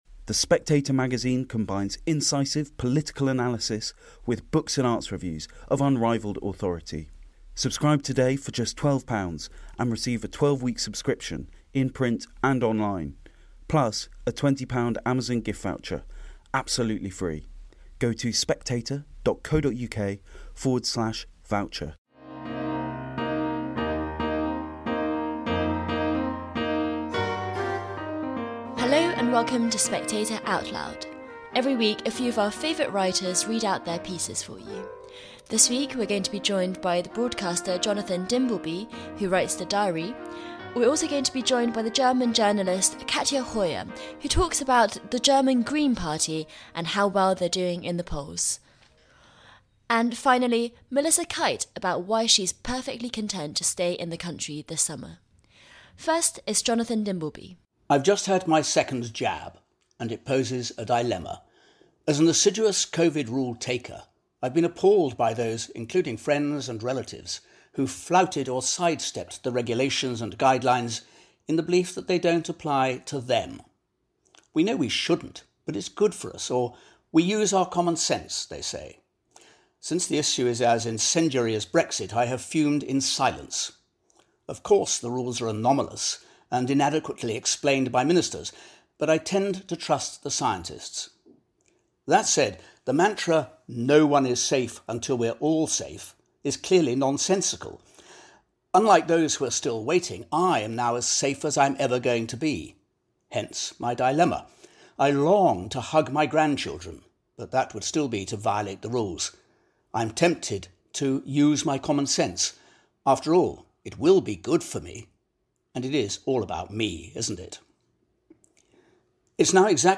News Commentary, News, Daily News, Society & Culture